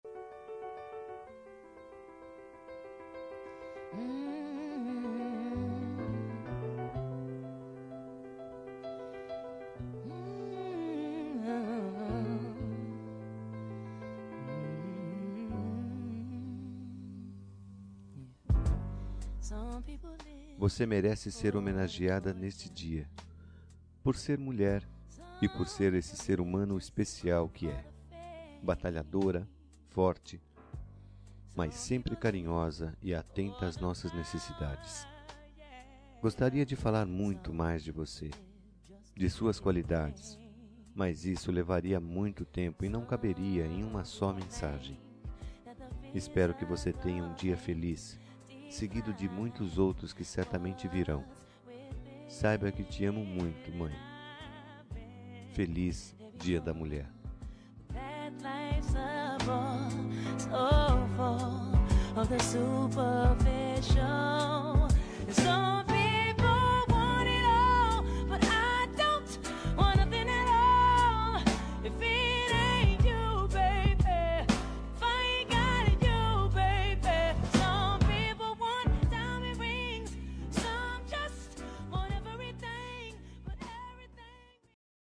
Telemensagem Dia das Mulheres para Mãe – 02 Voz Masculina